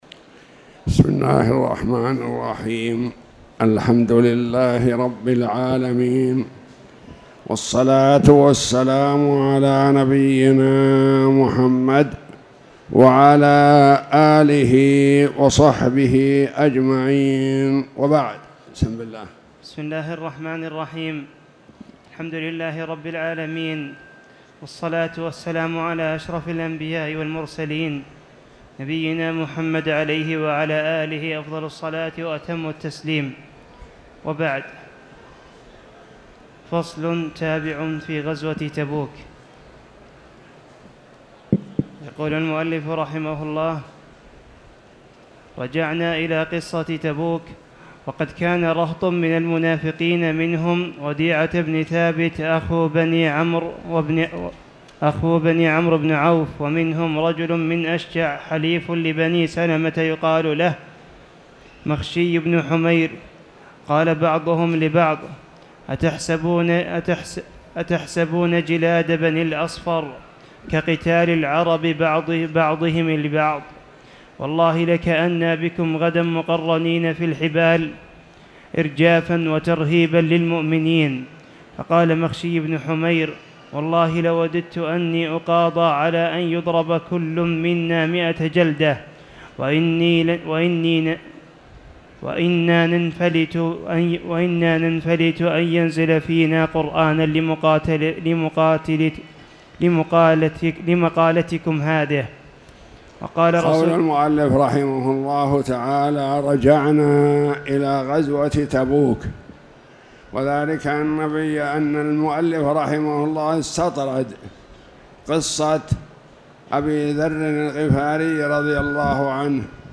تاريخ النشر ٢ ذو القعدة ١٤٣٧ هـ المكان: المسجد الحرام الشيخ